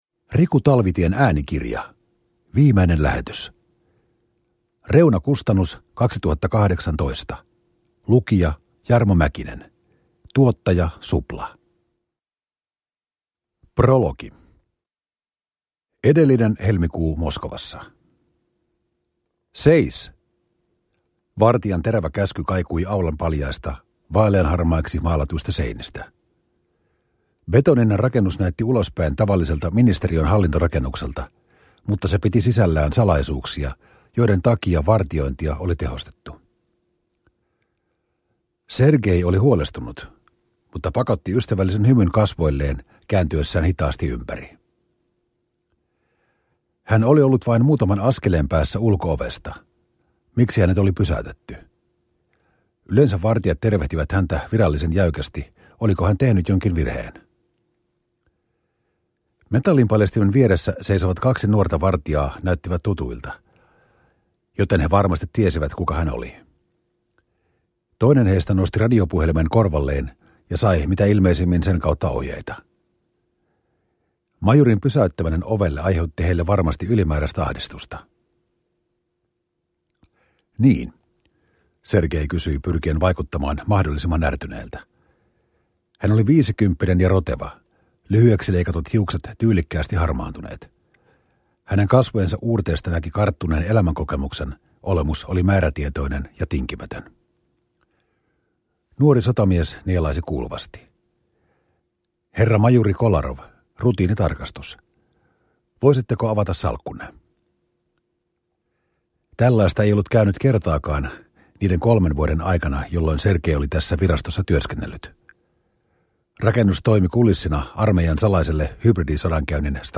Viimeinen lähetys – Ljudbok – Laddas ner